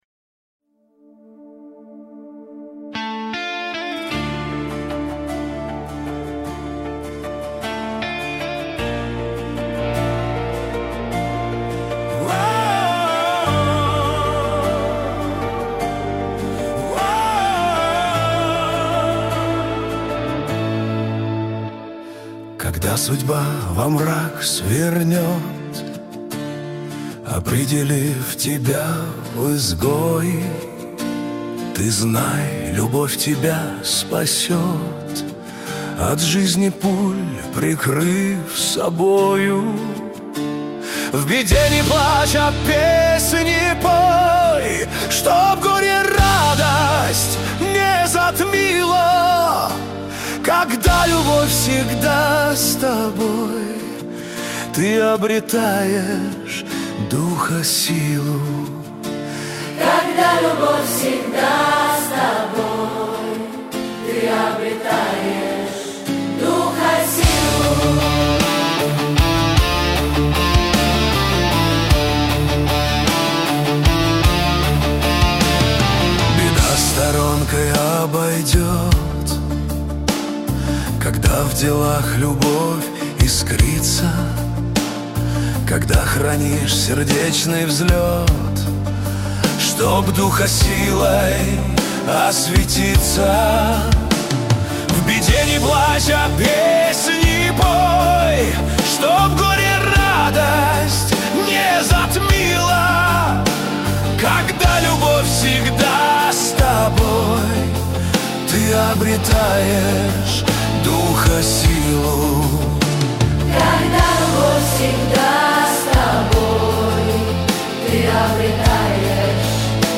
кавер-версия на мотив марша 1929-30 гг.